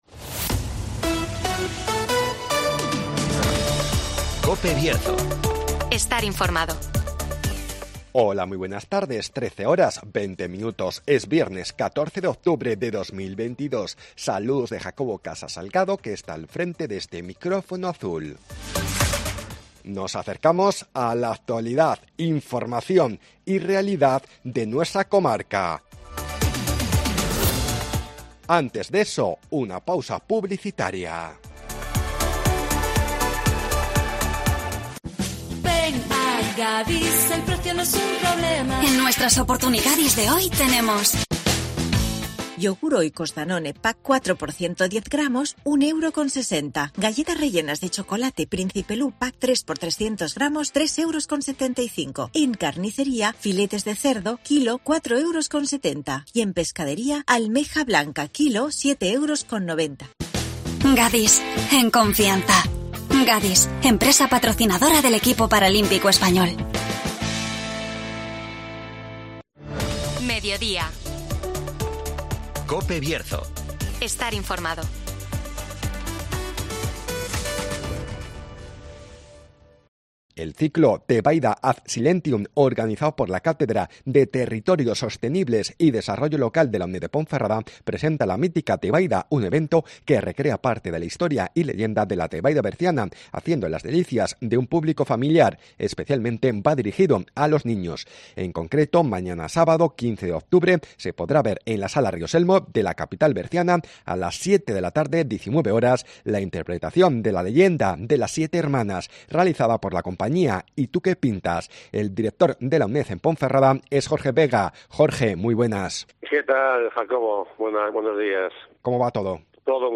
El ciclo Tebaida ad Silentium presenta la 'Leyenda de las 7 hermanas' (Entrevista